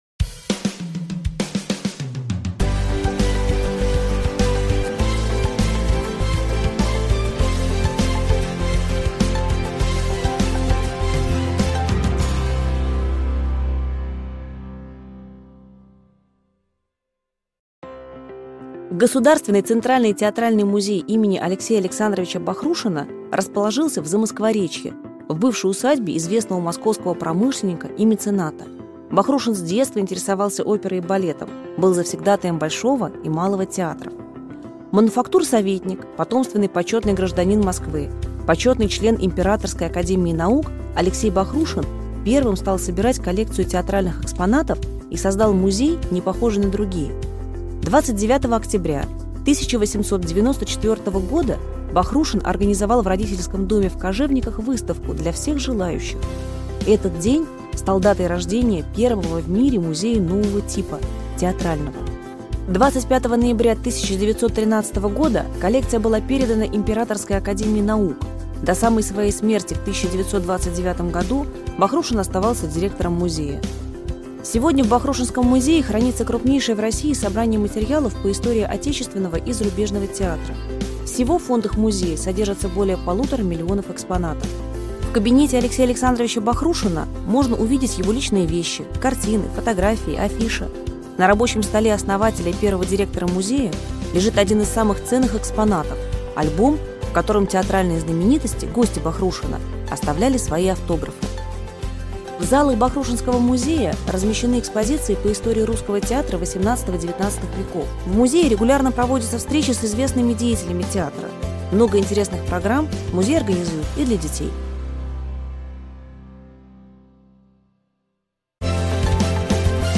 Аудиокнига Маяковский танцует фокстрот: социальные танцы 20-х годов | Библиотека аудиокниг